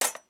SFX_Metal Sounds_05.wav